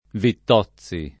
Vittozzi [ vitt 0ZZ i ]